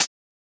Closed Hats
edm-hihat-34.wav